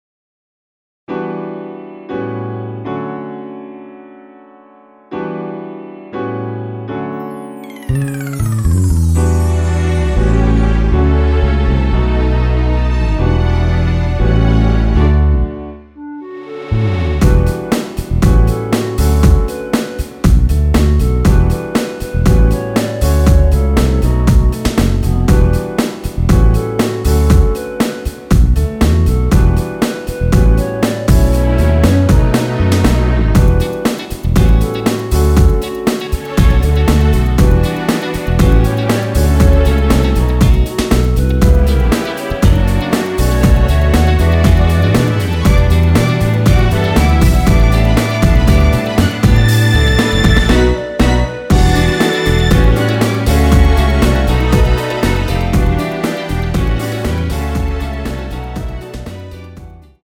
원키 멜로디 포함된 MR입니다.
앞부분30초, 뒷부분30초씩 편집해서 올려 드리고 있습니다.
중간에 음이 끈어지고 다시 나오는 이유는